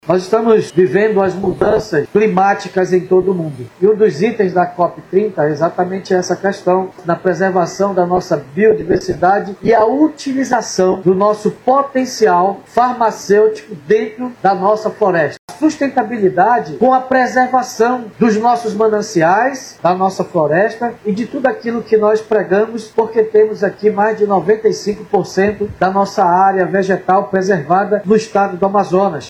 A “Farmácia Viva” segue diretrizes do Ministério da Saúde, integrando o Sistema Único de Saúde (SUS) e abrangendo todas as etapas do processo produtivo: desde o cultivo e colheita das plantas até a preparação e distribuição dos medicamentos naturais nas unidades básicas de saúde. A proposta busca oferecer alternativas terapêuticas seguras e acessíveis, baseadas em produtos de origem vegetal, destaca o prefeito de Manaus, David Almeida.